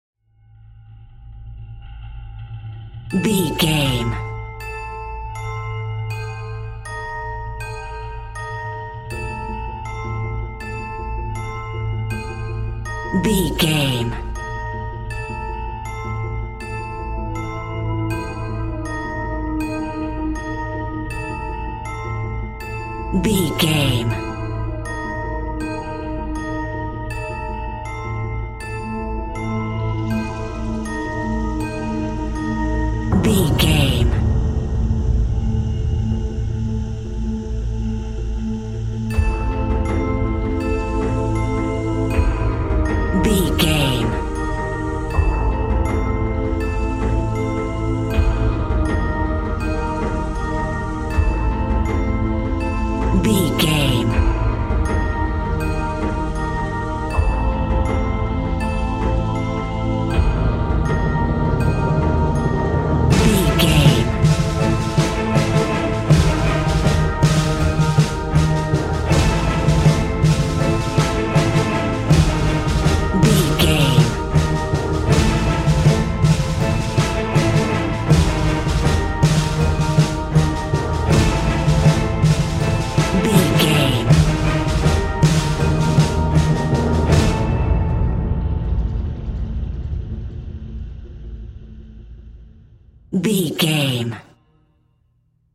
Lydian
strings
percussion
synthesiser
ominous
dark
suspense
haunting
creepy